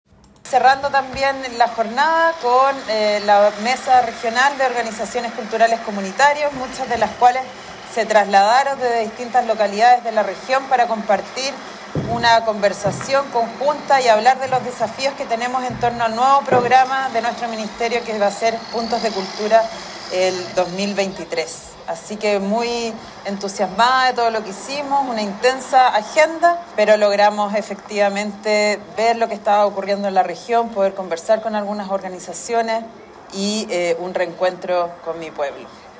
AUDIO : Julieta Brodsky – Ministra de las Culturas